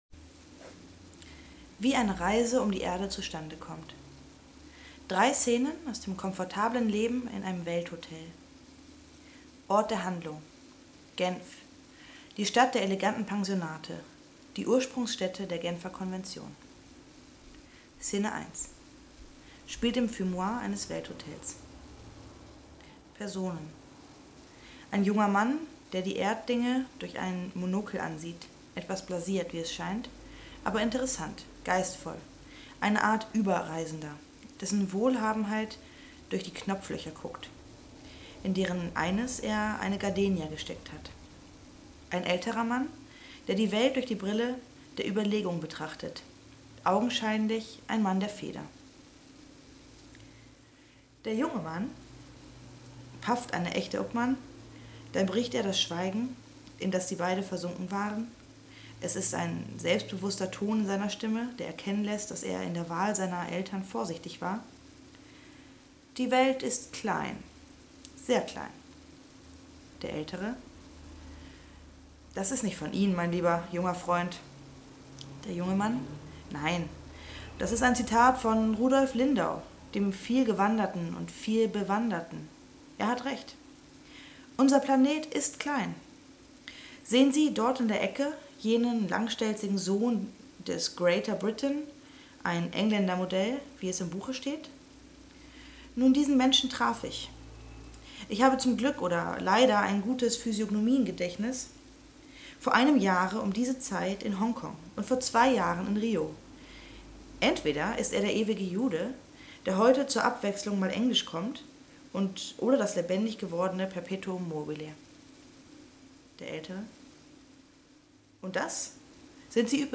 Und ich möchte mich an dieser Stelle einmal entschuldigen: die Aufnahmequalität ist nicht optimal.